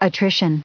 430_attrition.ogg